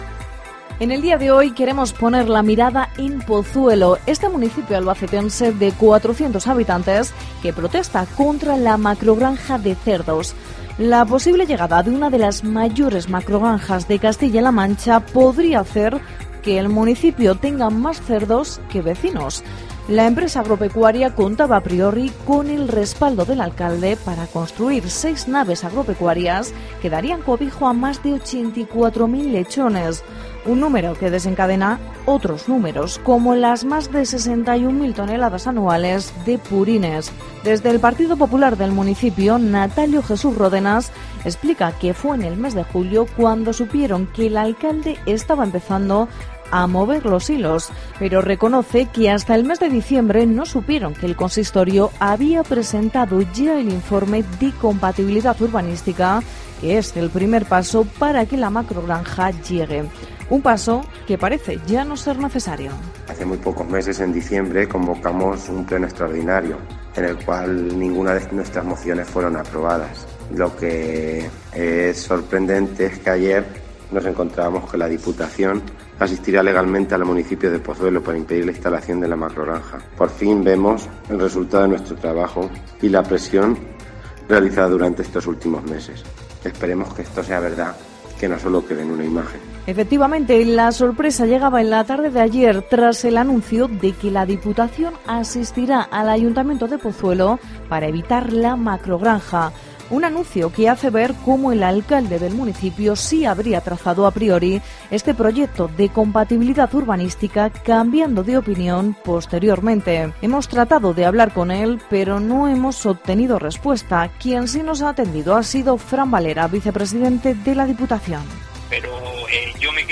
Audio con Natalio Jesús, portavoz del PP en Pozuelo y Fran Valera, vicepresidente de la Diputación